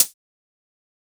BEAT HAT 06.WAV